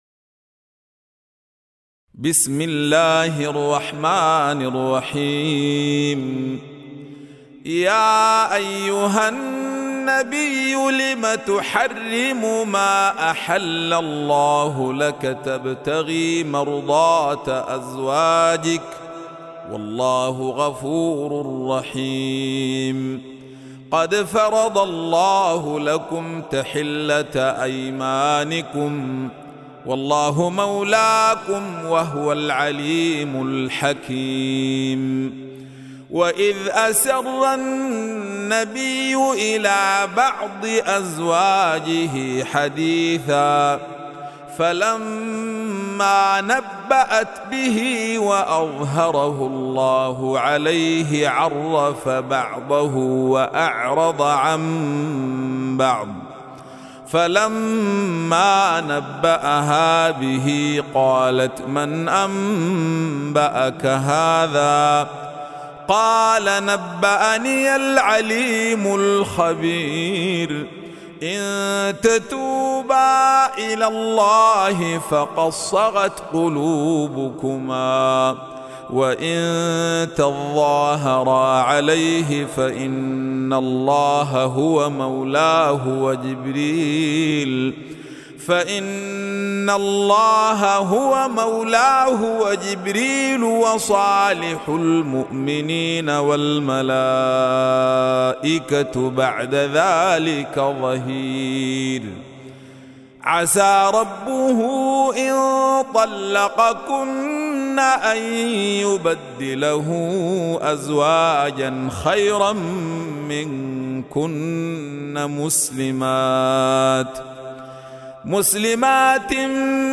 Surah-At-Tahreem Recitation by Noreen Muhammad
The beautiful voice of noreen muhmmad siddiq.